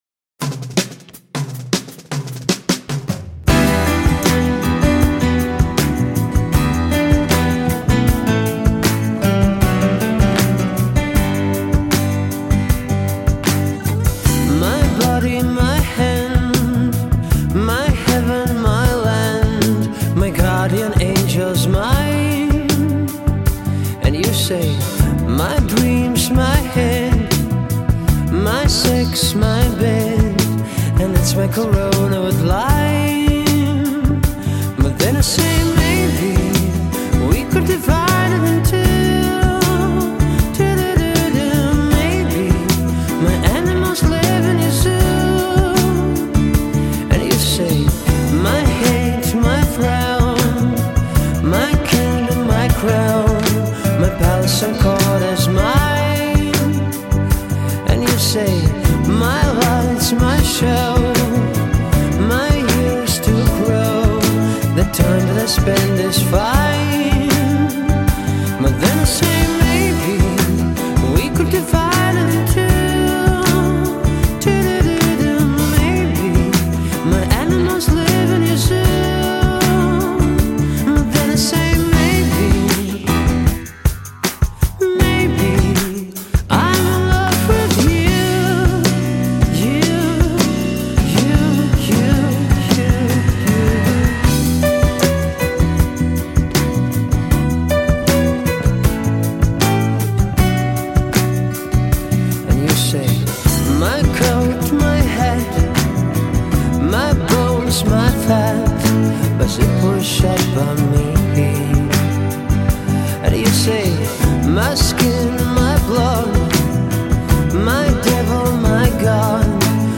pop rock